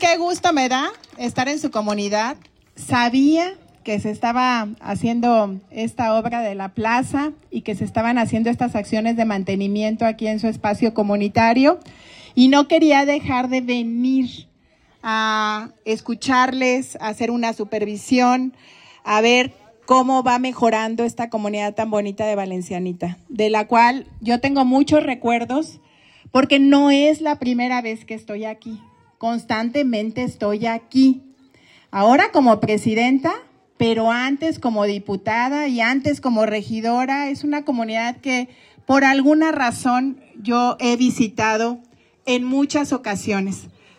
AudioBoletines
Lorena Alfaro García, presidenta municipal